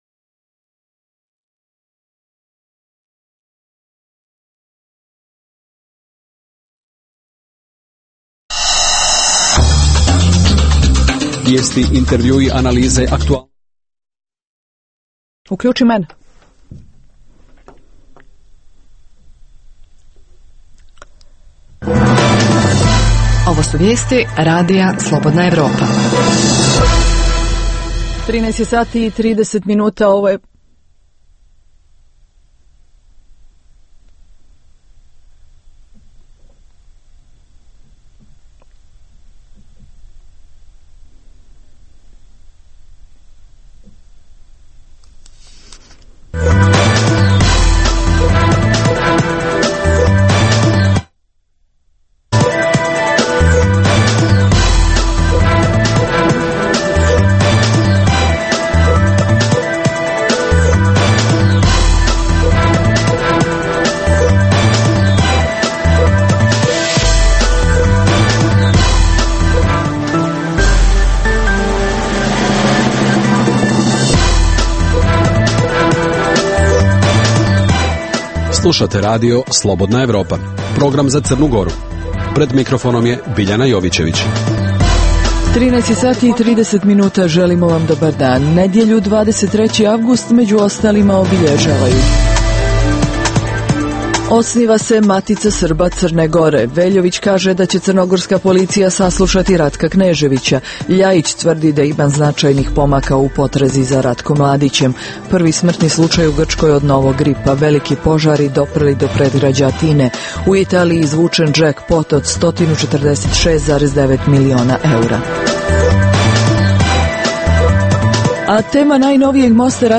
Emisija namijenjena slušaocima u Crnoj Gori. Sadrži lokalne, regionalne i vijesti iz svijeta, rezime sedmice, intervju "Crna Gora i region", tematske priloge o aktuelnim dešavanjima u Crnoj Gori i temu iz regiona.